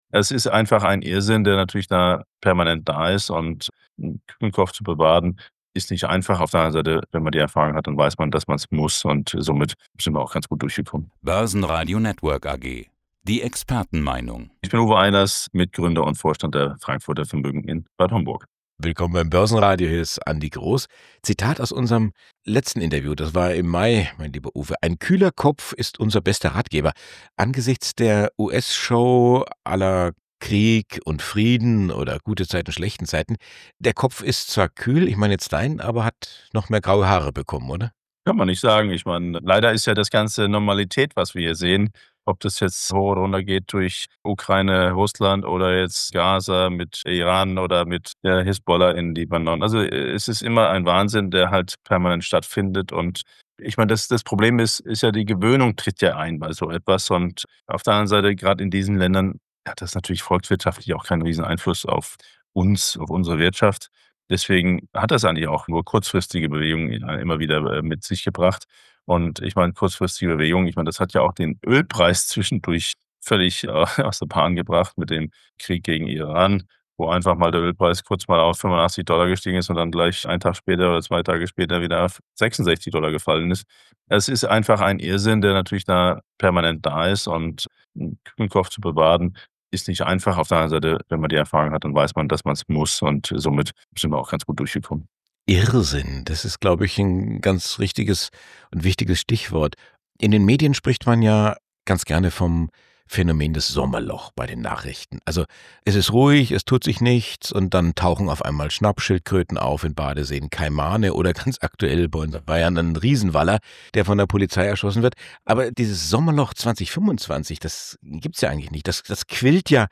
Radiobeitrag: Kühler Kopf in heißen Zeiten.